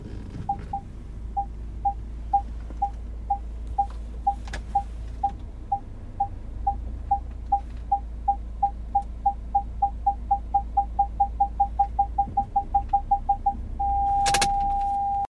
На этой странице собраны звуки парктроника — сигналы, которые издает автомобиль при парковке или обнаружении препятствий.
Звуки парковки авто: аккуратный задний ход, сигнал парктроника и фиксация ручником